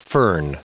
Prononciation du mot fern en anglais (fichier audio)
Prononciation du mot : fern